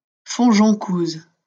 Fontjoncouse (French pronunciation: [fɔ̃ʒɔ̃kuz]